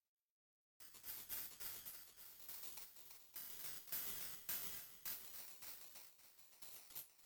フリー音源「不安な効果音」 登録不要、無料でダウンロード可能 | のざのざノート
不安な音
臨場感あふれる不安を誘う効果音。